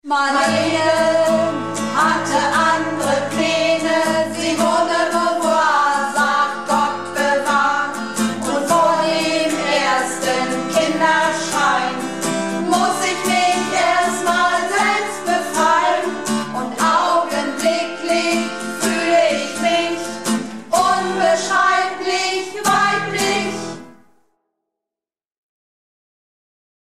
Projektchor "Keine Wahl ist keine Wahl" - Probe am 21.05.19
Runterladen (Mit rechter Maustaste anklicken, Menübefehl auswählen)   Unbeschreiblich weiblich (Hohe Stimme)